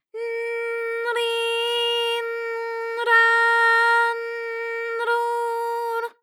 ALYS-DB-001-JPN - First Japanese UTAU vocal library of ALYS.
r_n_ri_n_ra_n_ru_r.wav